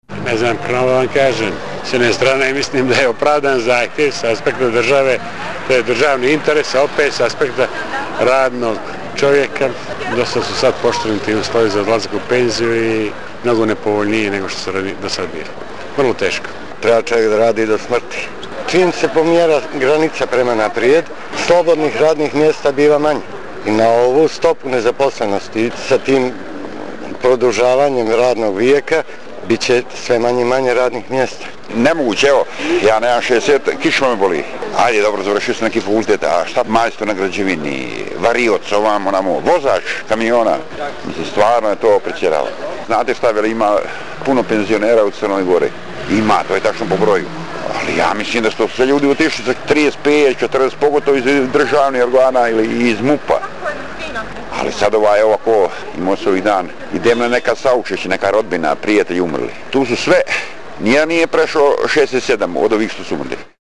Anketa: Podgoričani o penzionoj reformi